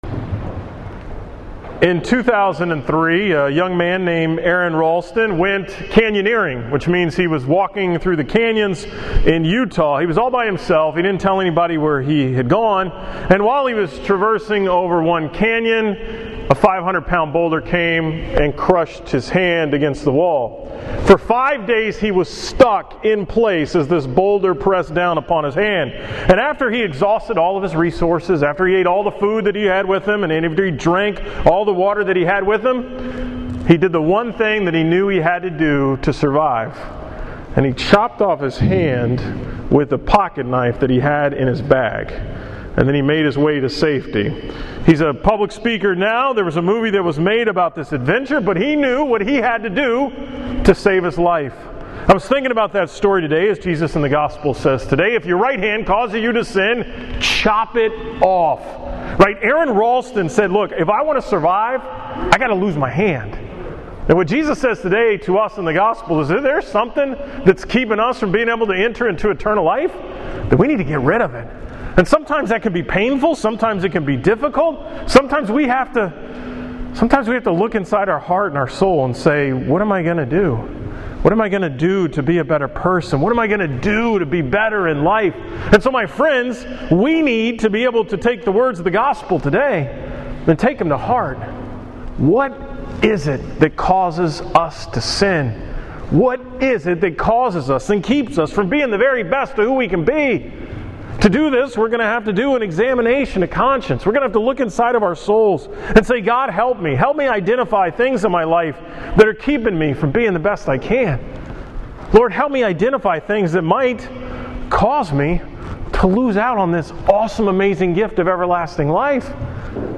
From the 8 am Mass at St. Francis of Assisi on Sunday, September 27, 2015.